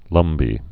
(lŭmbē)